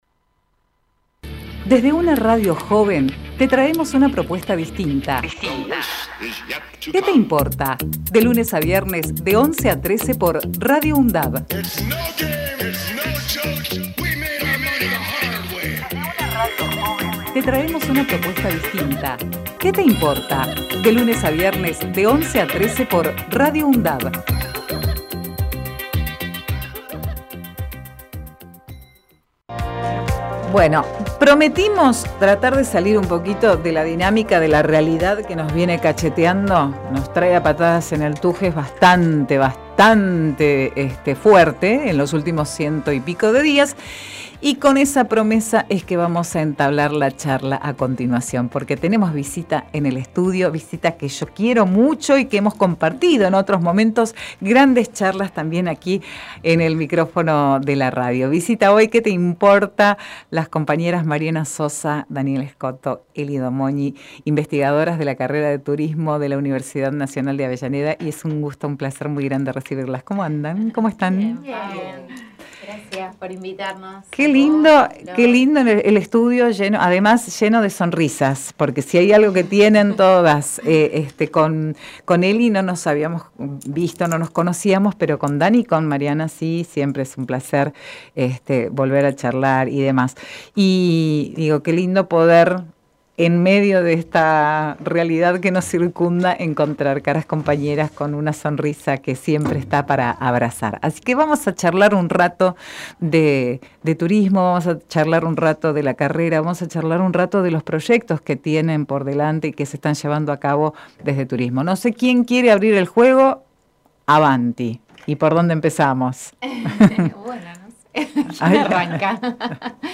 DOCENTES DE TURISMO Texto de la nota: Compartimos entrevista realizada en "Que te Importa" con las docentes.